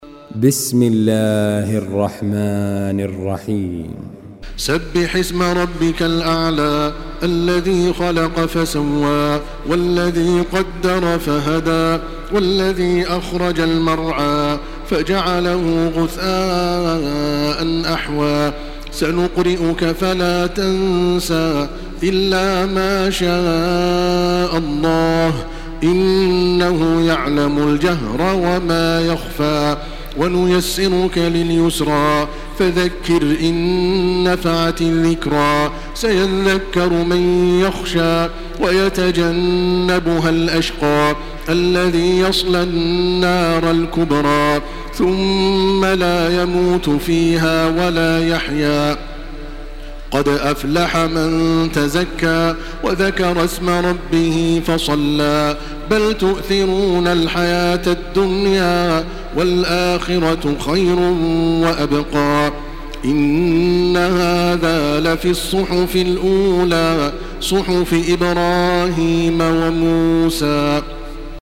Surah الأعلى MP3 by تراويح الحرم المكي 1429 in حفص عن عاصم narration.
مرتل